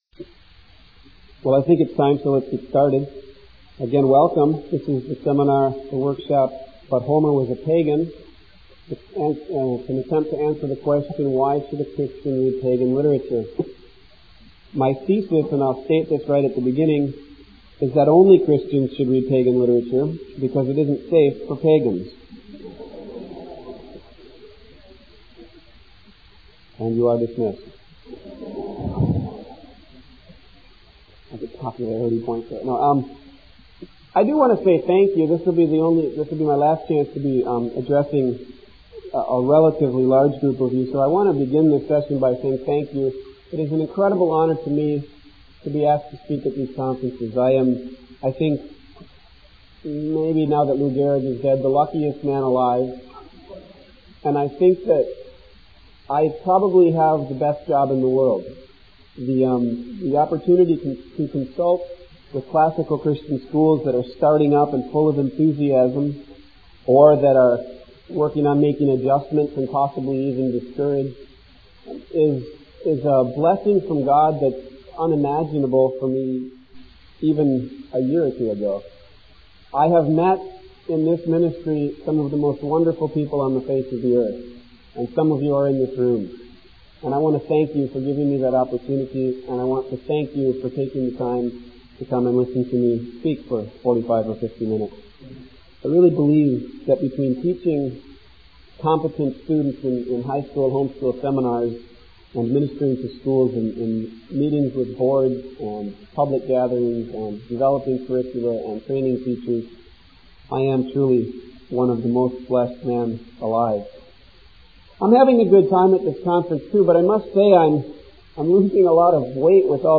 2000 Workshop Talk | 0:53:25 | All Grade Levels, Literature
The Association of Classical & Christian Schools presents Repairing the Ruins, the ACCS annual conference, copyright ACCS.